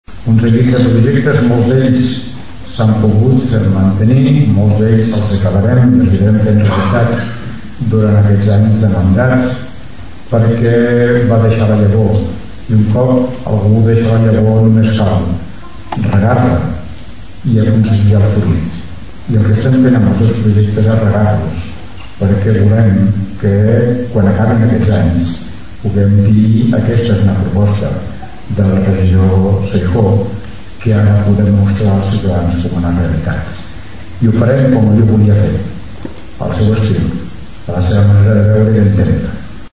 Va ser un acte senzill, però emotiu: diversos parlaments i algunes projeccions amb imatges de la seva vida política, acompanyades de música en directe, van servir per recordar-lo.